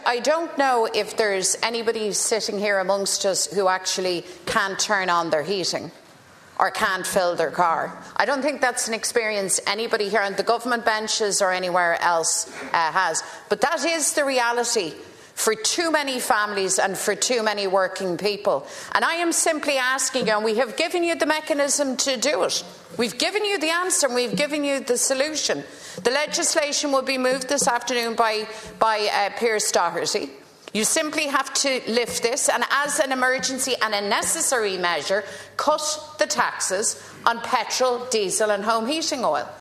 A short time ago, his party leader Mary Lou McDonald urged Justice Minister Jim O’Callaghan to support and adopt that bill……..